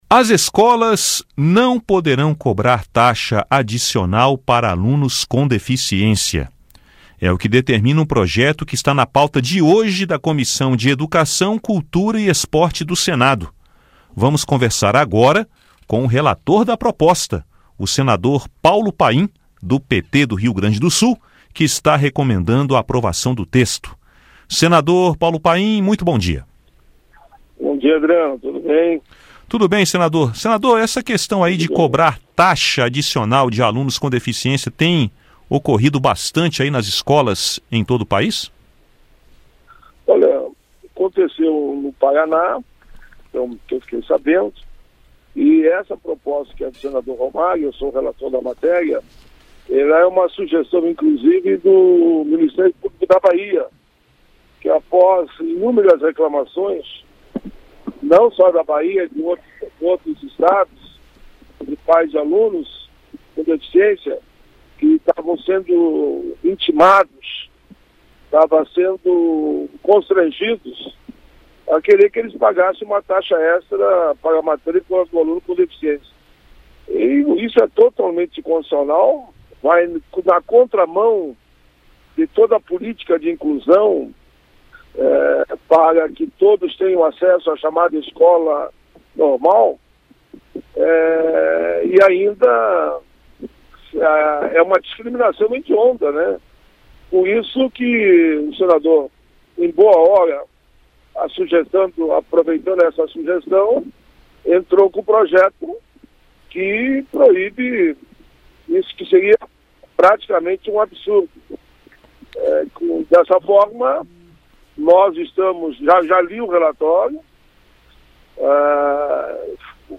Paim conversou sobre o projeto